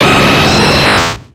Cri de Sulfura dans Pokémon X et Y.